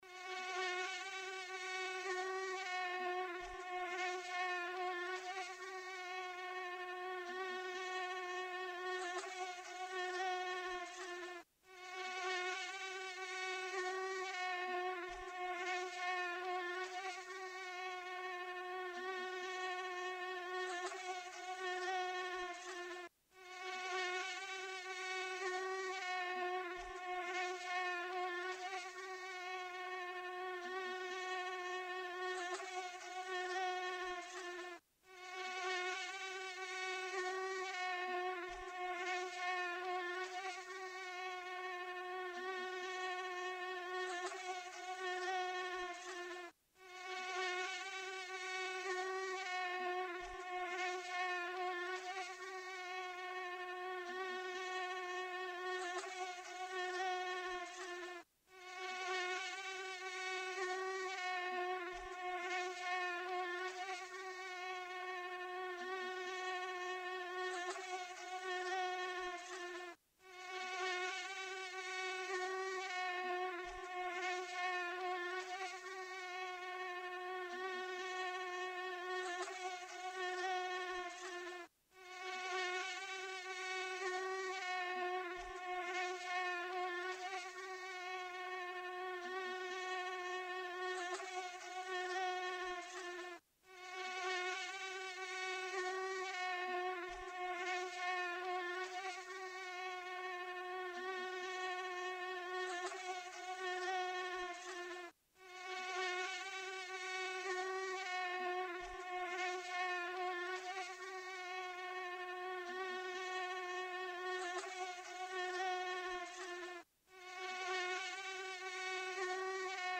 На этой странице собраны звуки писка комаров – от раздражающего высокочастотного звона до приглушенного жужжания.
комар летает и жужжит